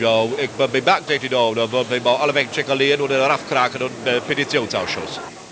B�rgermeister Wilhelm Oelgem�ller (SPD) �ber das platte Deutsch im Bundestag.